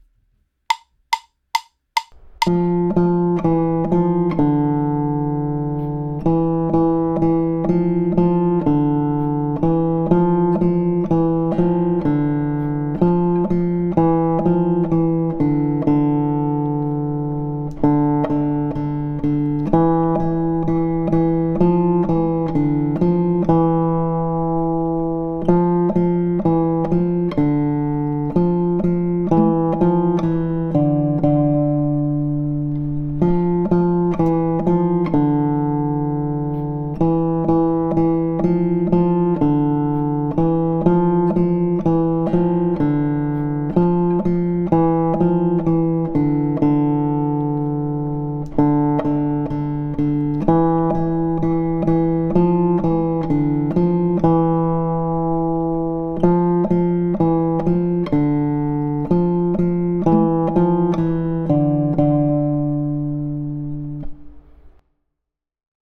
First and Only should be played allegro.
First and Only | Melody only.
First_and_Only_GTR_melody.mp3